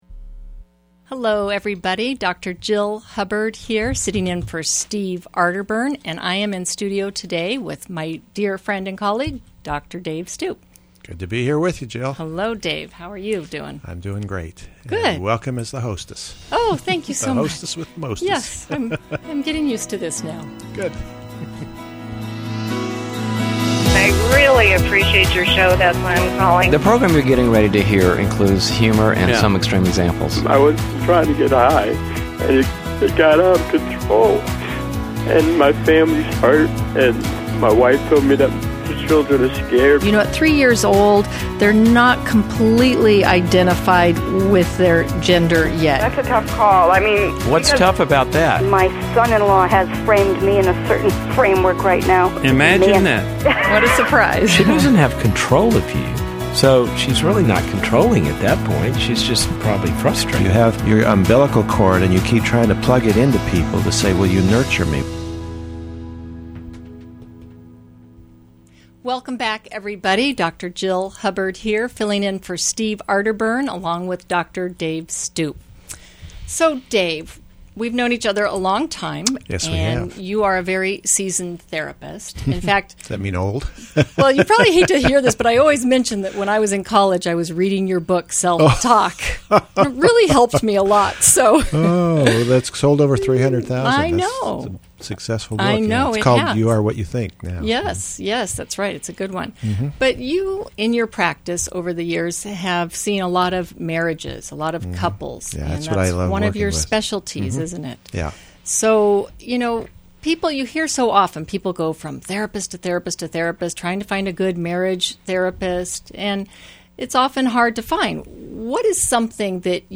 Caller Questions: 1. How do I confront my husband after fighting 11 out of 12 months of marriage? 2.